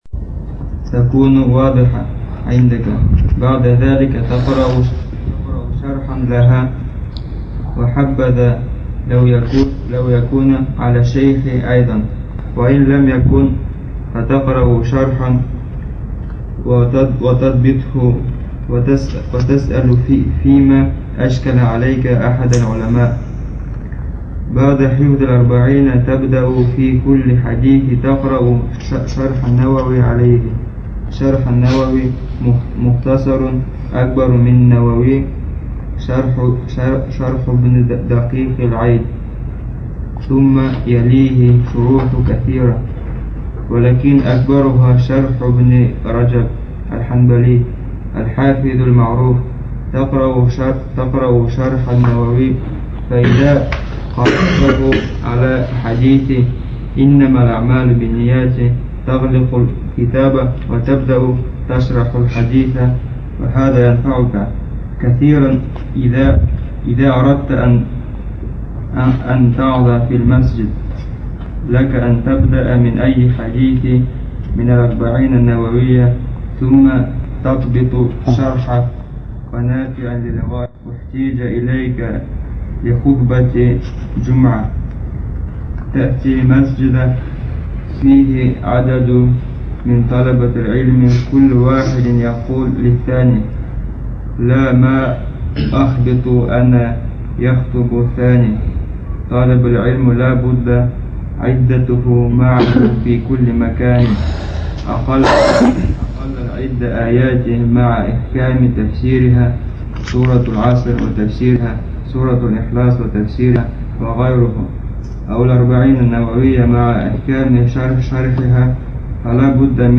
Материал:Лекция шейха Салиха бин АбдульАзиз Содержание:описание каким образом следует требовать знания